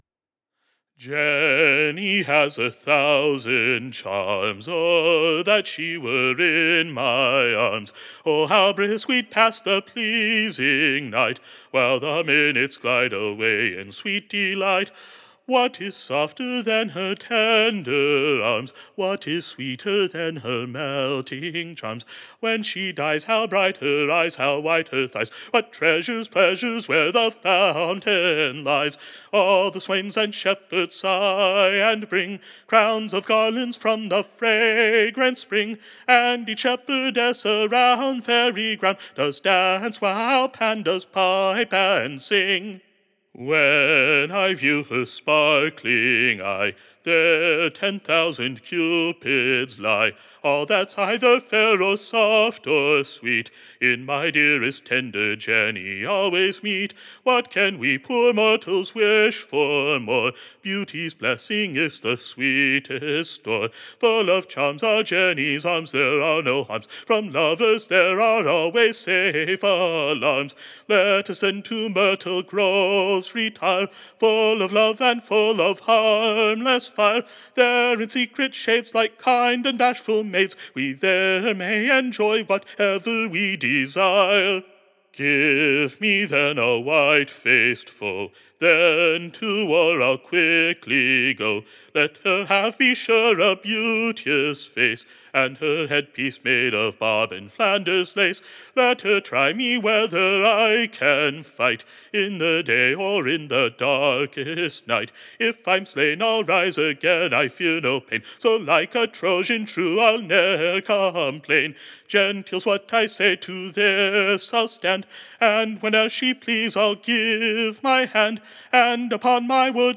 Recording Information Ballad Title Beauteous JENNY: / OR, / The Maiden Mistress. Tune Imprint To an Excellent New Scotch Tune.